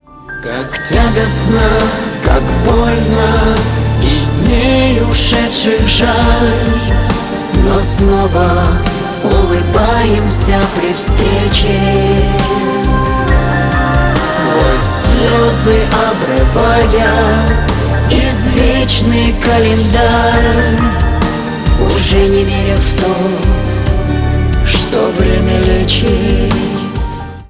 Шансон
спокойные